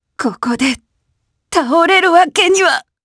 Demia-Vox_Dead_jp_b.wav